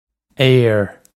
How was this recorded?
This comes straight from our Bitesize Irish online course of Bitesize lessons.